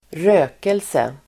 Ladda ner uttalet
rökelse substantiv, incense Uttal: [²r'ö:kelse] Böjningar: rökelsen, rökelser Definition: växtämnen som luktar gott vid förbränning incense substantiv, rökelse Förklaring: växtämnen som luktar gott vid förbränning